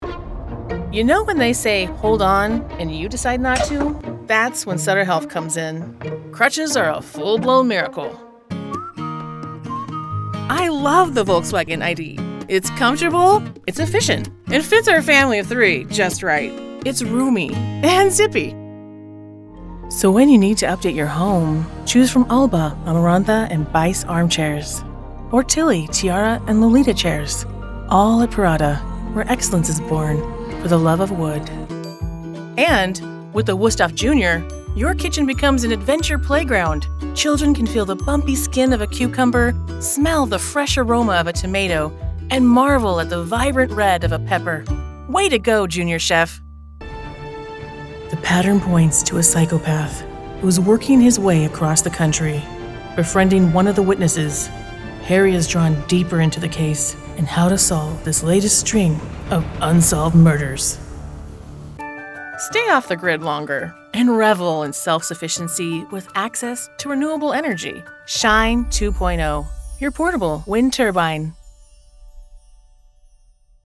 Empathic, Sincere, and Direct - Broadcast ready in San Francisco Bay Area
Middle Aged
I sound like the boss who is looking out for you, a reliable friend who keeps you informed, or a voice of calm when you need it most.
With my connected and sound treated home studio, we can work together to create the ideal professional voice over for your narration and commercial projects -- delivered on time to your specifications.